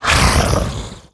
role4_wound2.wav